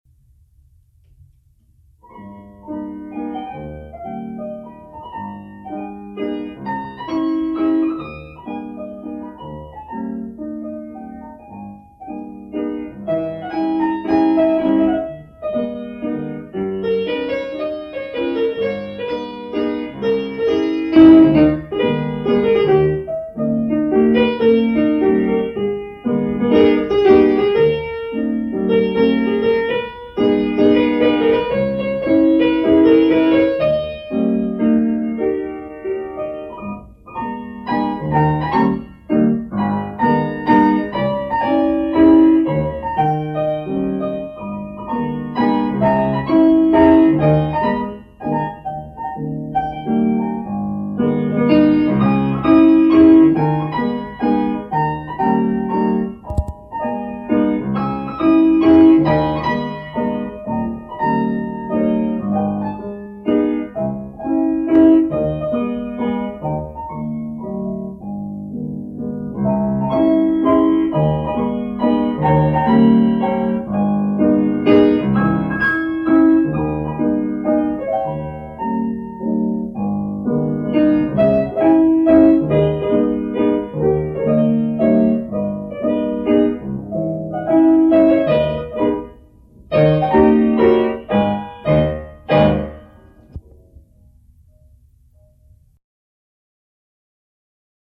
instant piano improvisations